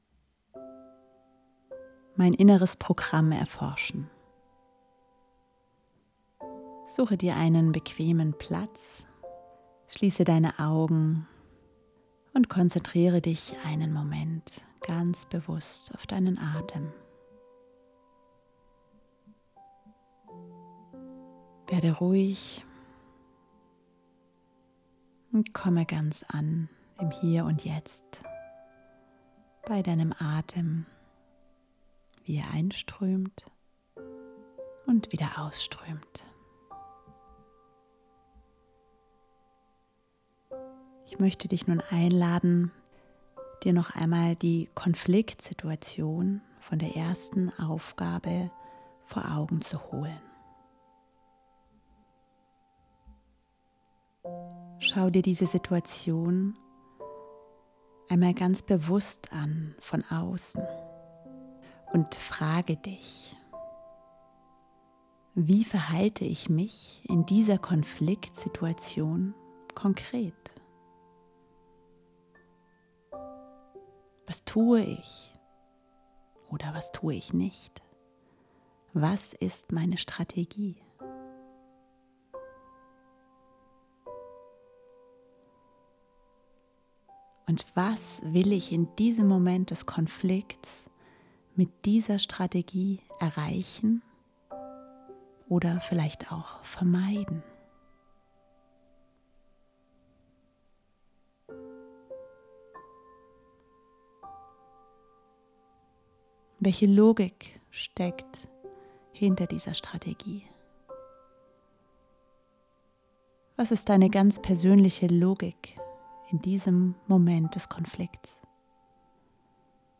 Gedankenreise: Inneres Programm